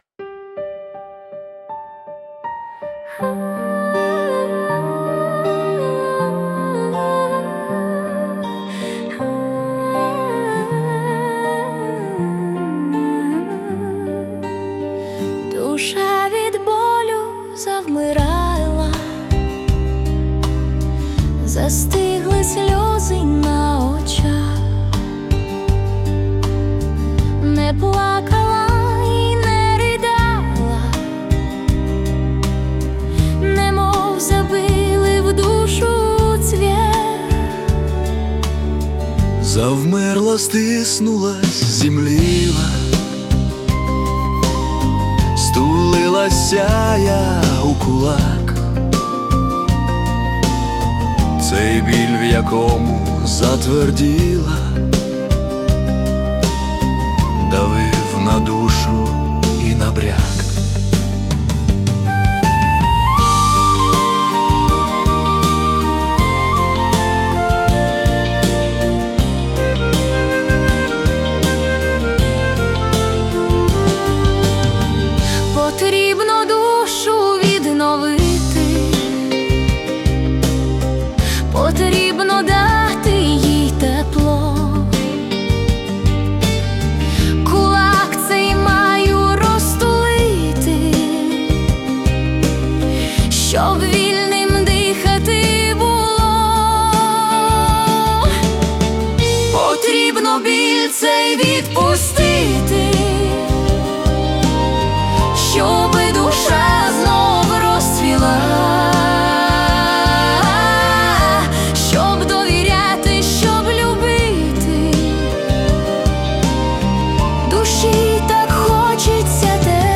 Folk Ballad / Confession
це камерна фолк-балада (80 BPM), що звучить як тиха сповідь.